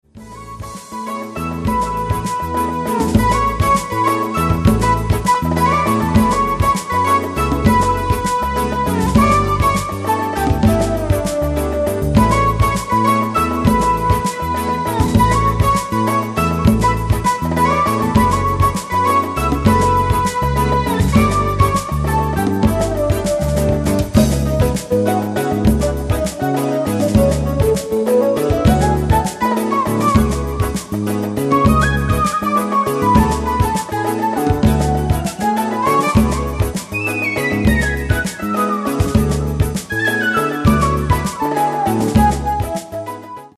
Jazz-fusion.